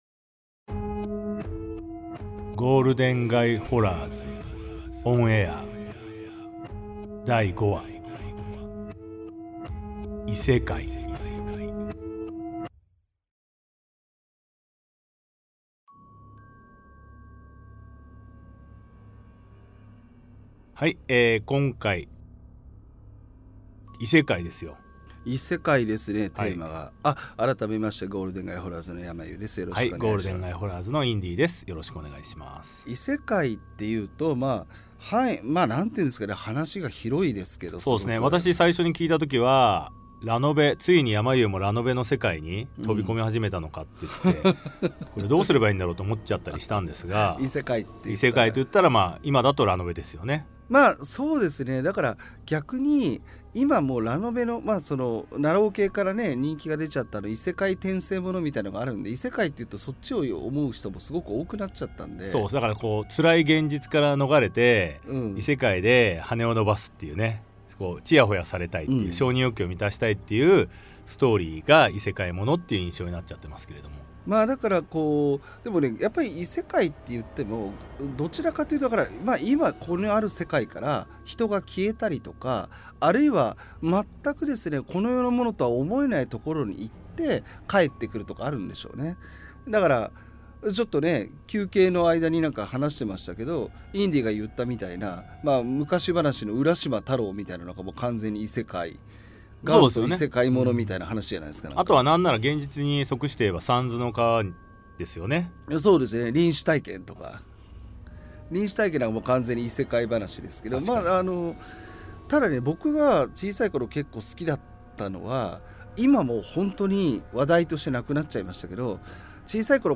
対談形式のホラー番組